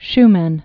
(shmĕn)